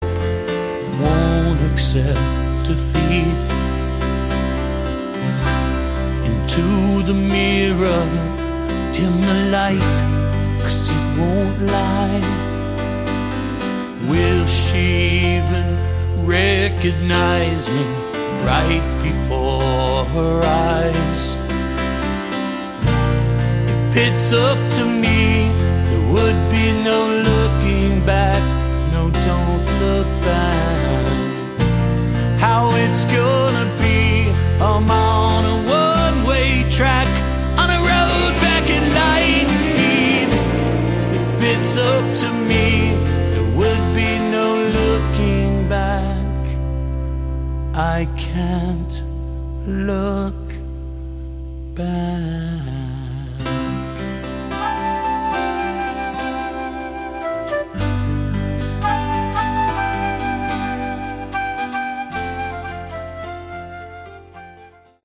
Category: Melodic Metal
all vocals and instruments